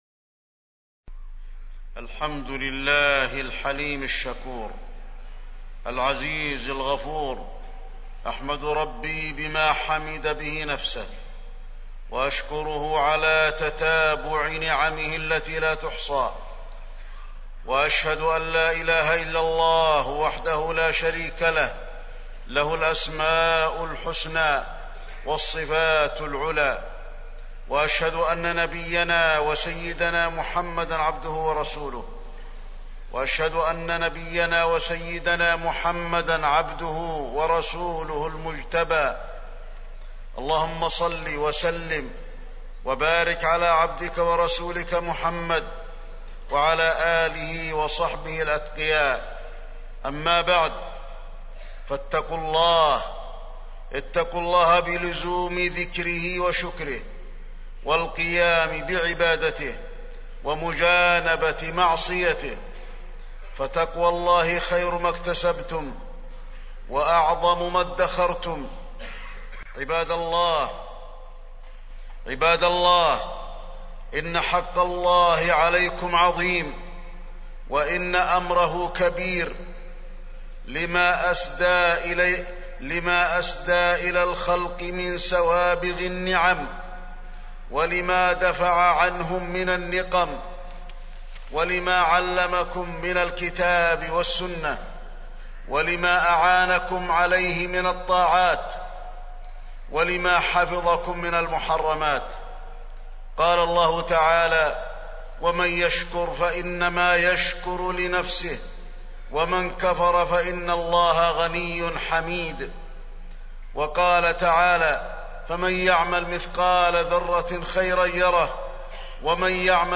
تاريخ النشر ٢ شوال ١٤٢٦ هـ المكان: المسجد النبوي الشيخ: فضيلة الشيخ د. علي بن عبدالرحمن الحذيفي فضيلة الشيخ د. علي بن عبدالرحمن الحذيفي المداومة على العبادة بعد رمضان